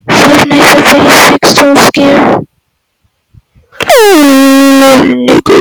fnaf 6 jump fart
fnaf-6-jump-fart.mp3